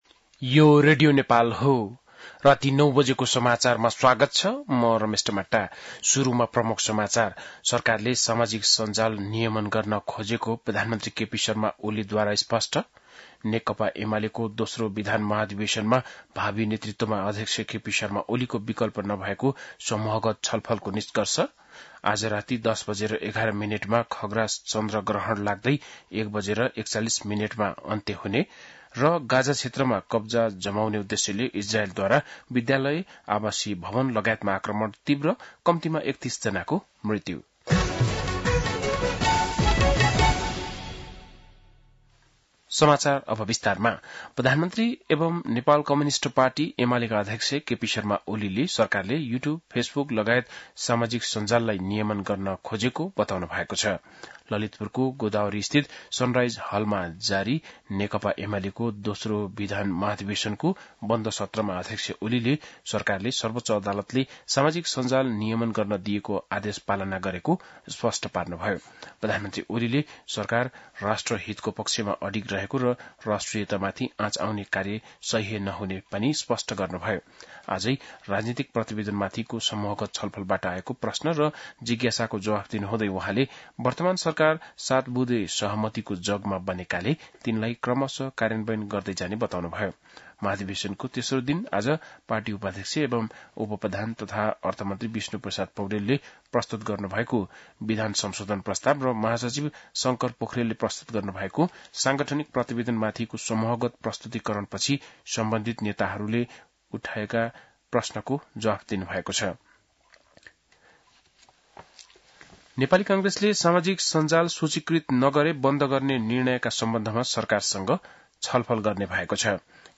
बेलुकी ९ बजेको नेपाली समाचार : २२ भदौ , २०८२
9-pm-nepali-news-1-1.mp3